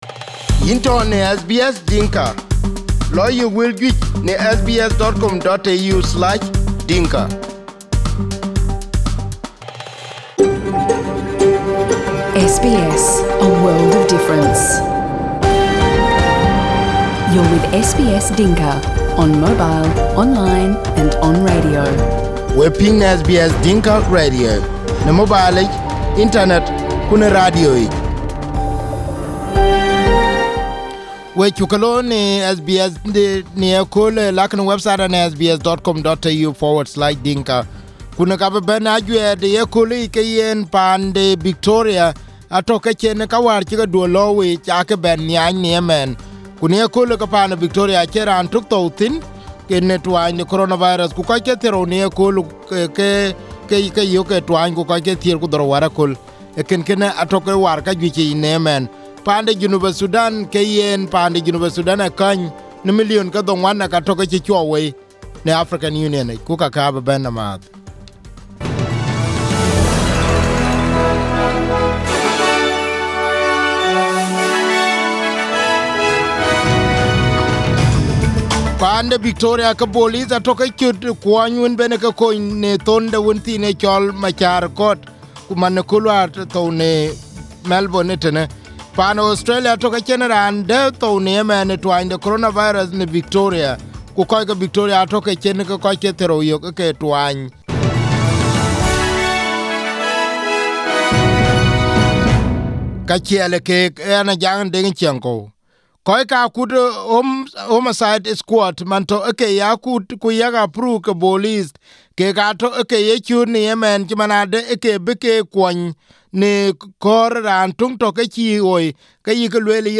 SBS Dinka News- 24/06/2020 11:00:00 AM